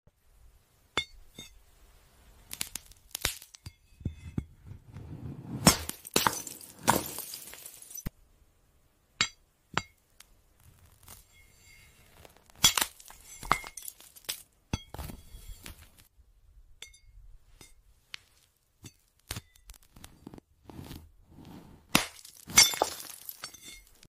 ASMR glass cutting🤍 Ai asmr 🫶🏻 .